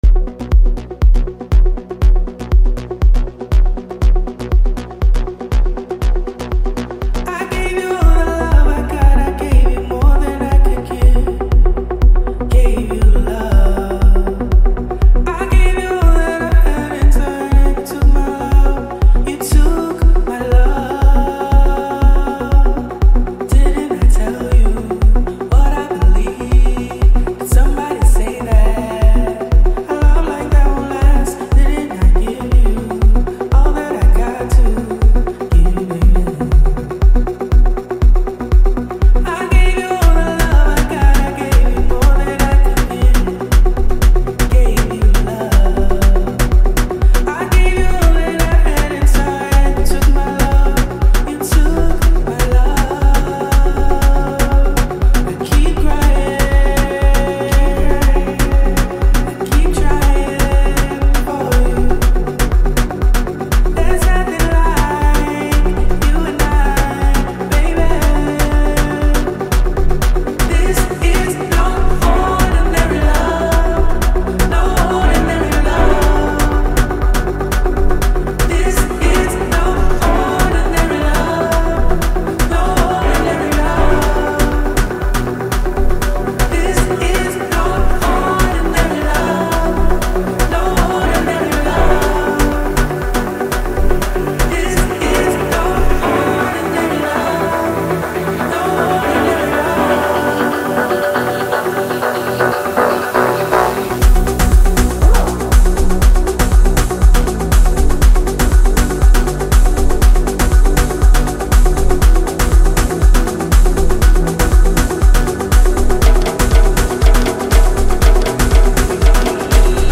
South African singer-songwriter